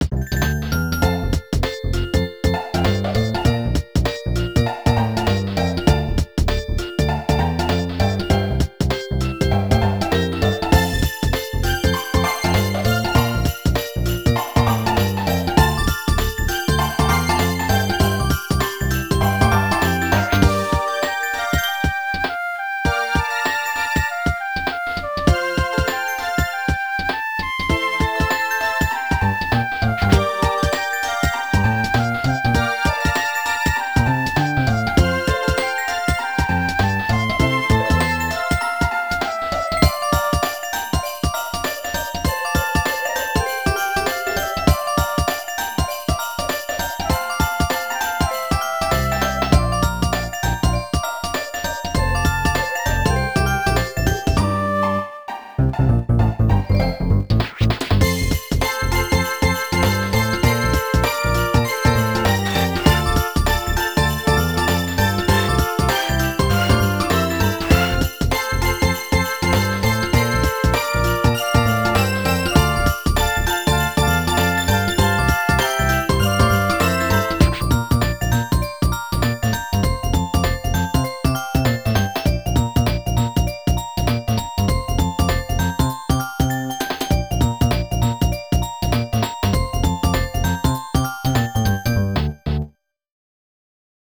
This file is an audio rip from a(n) Nintendo DS game.